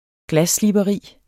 Udtale [ ˈglasslibʌˌʁiˀ ]